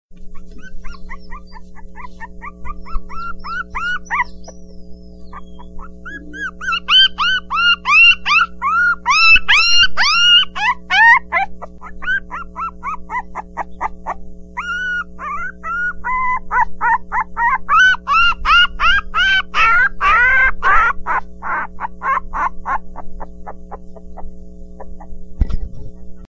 Animals sounds